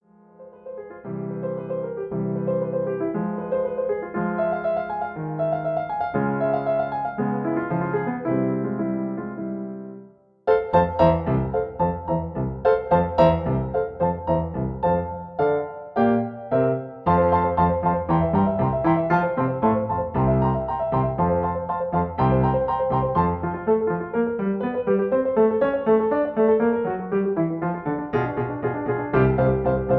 In B-flat Major.